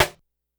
Snares
snr_70.wav